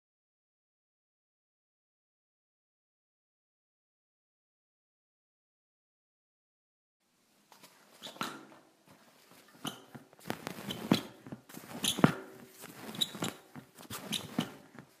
23 厕纸卷筒合并的
描述：被滚动的卫生纸金属路辗。很吵。不寻常的声音。
Tag: 嘈杂的 讨厌的 OWI 纸张 吱吱响 厕所